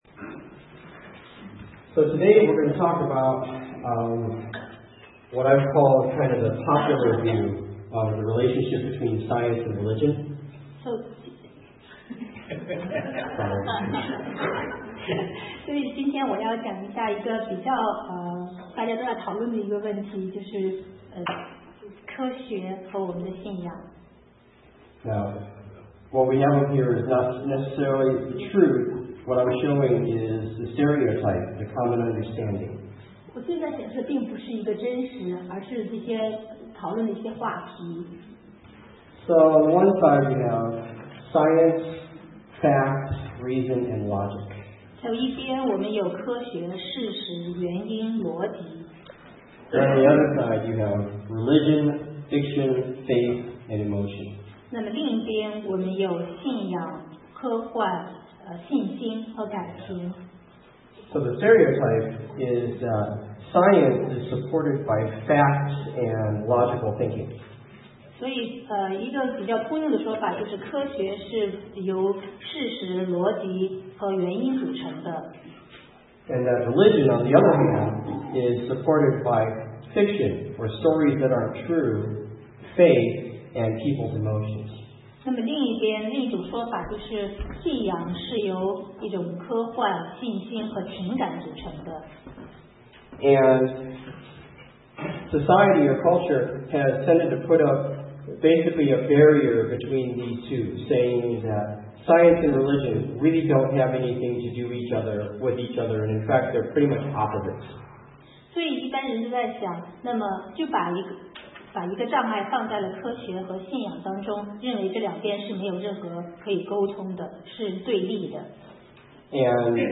Sermon 2010-05-16 Fact, Fiction, or Faith